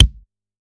soft-hitnormal.wav